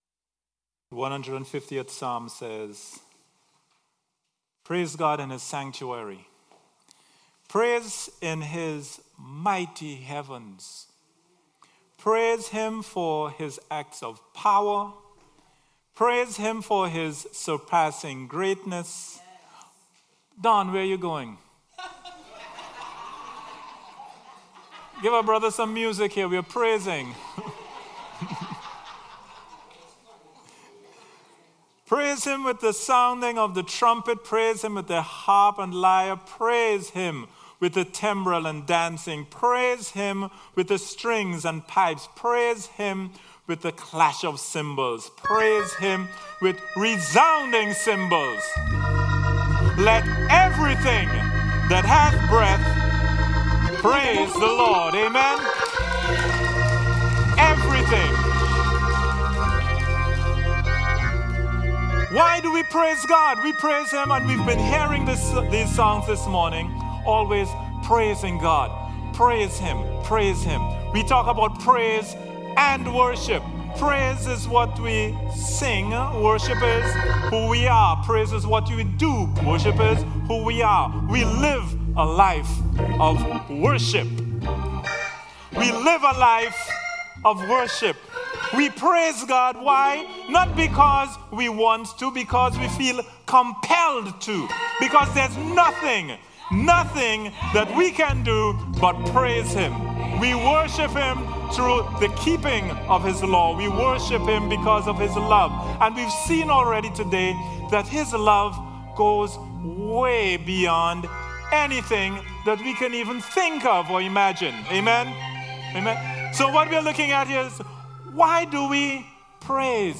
Worship Jam 9/23/18